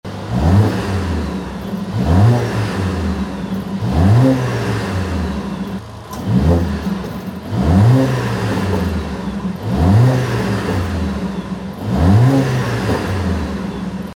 • AMG Performance Exhaust System: 90mm Quad-Tailpipes
Mercedes-benz-a45-s-revs.mp3